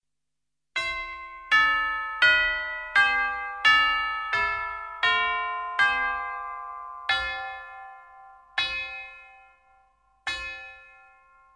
Recursos para Percusión
Campanas tubulares.mp3